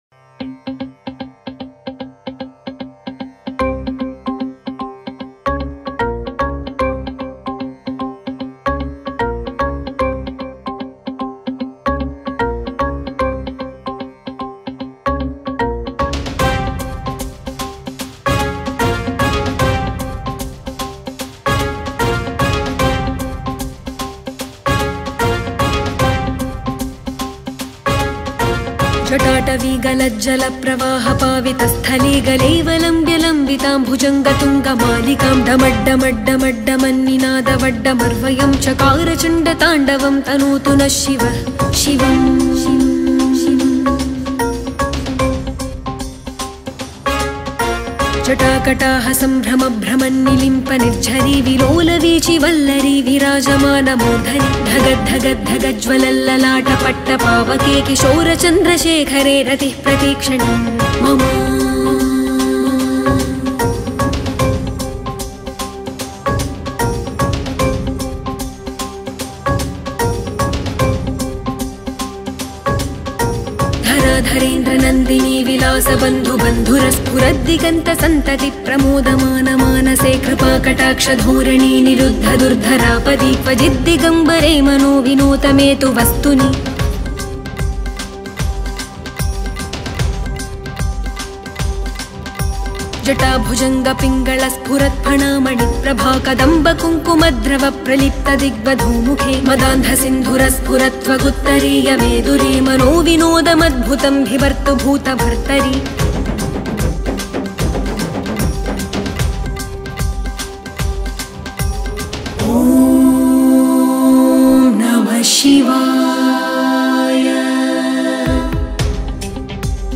Bhajan